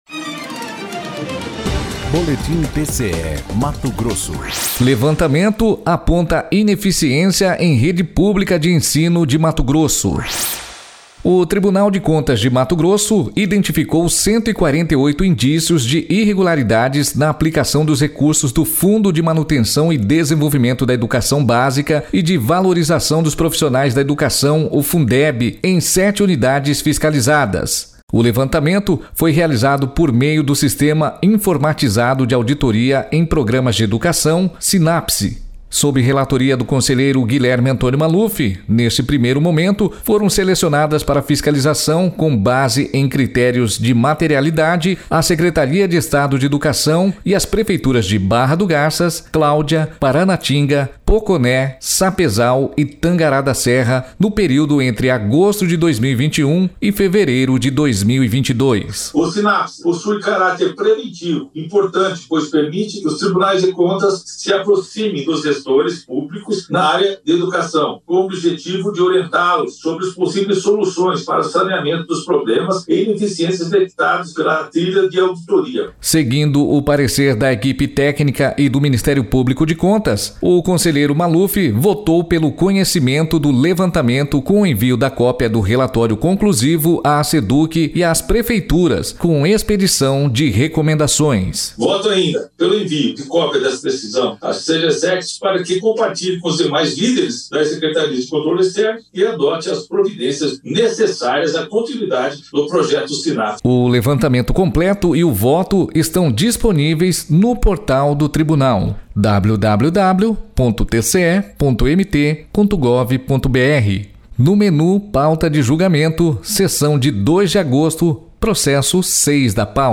Sonora: Guilherme Maluf – conselheiro do TCE-MT